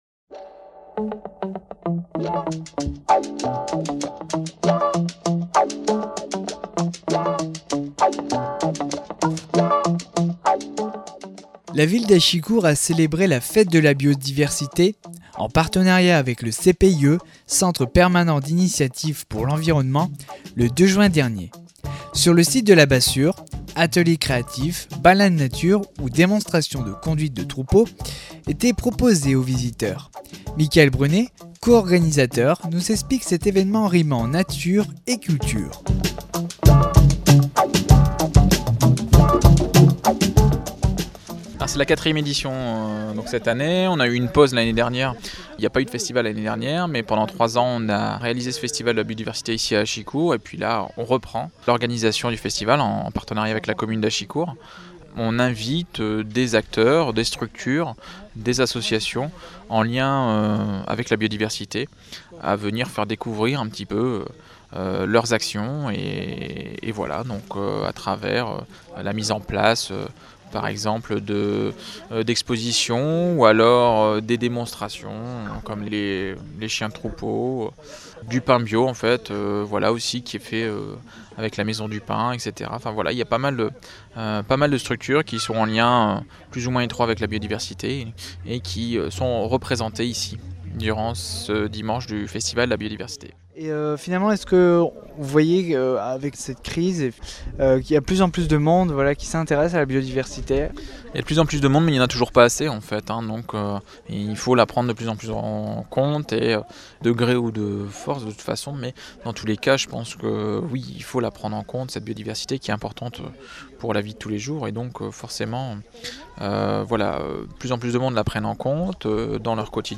Le 2 juin dernier, sur le site du Parc de la Bassure, Achicourt célébrait son Festival de la Biodiversité. Autour d’animations et ateliers, les différents acteurs arrageois de l’environnement ont évoqué et sensibilisé le public à la protection de la nature et des espèces.